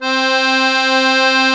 MUSETTE 1 .4.wav